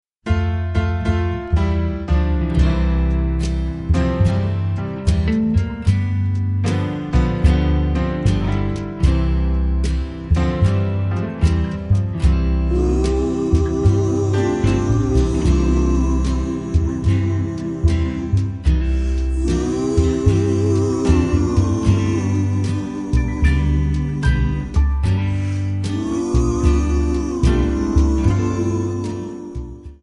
Backing track files: Oldies (1113)